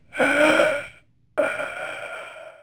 monster.wav